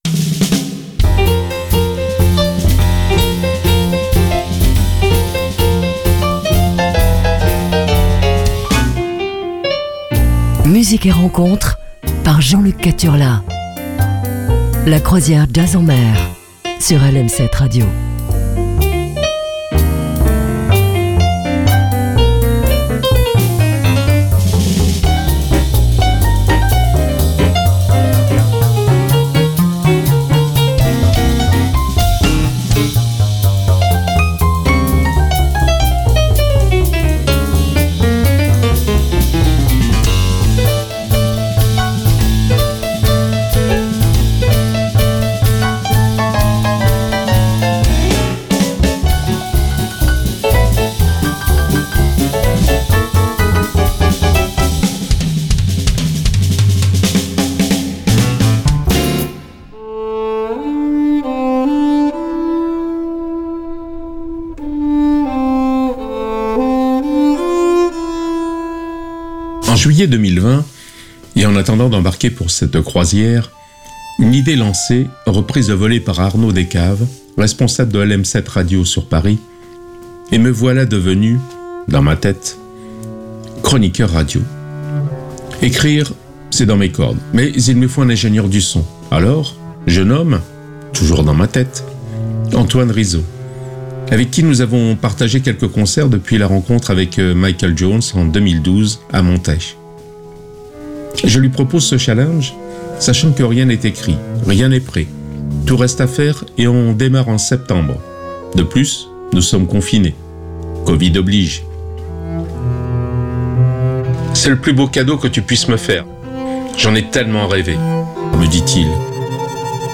rythmes jazzy